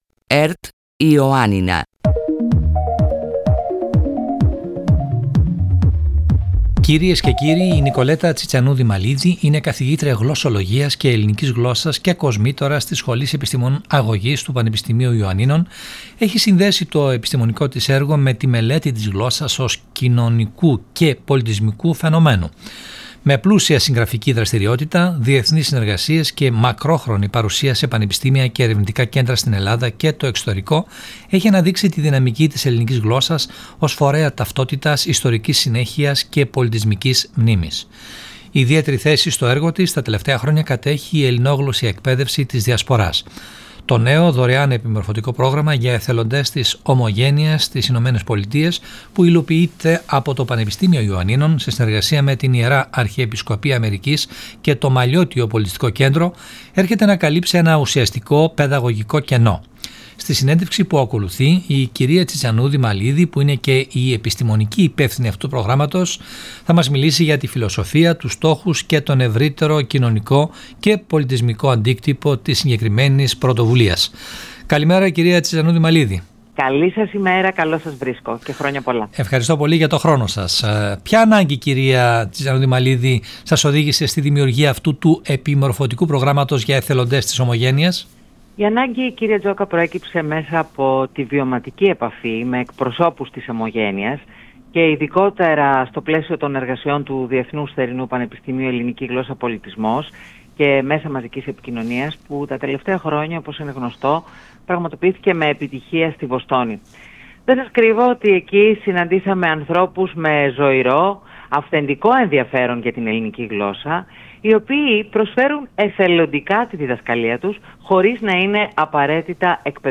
μίλησε στον αέρα του Περιφερειακού Σταθμού Ιωαννίνων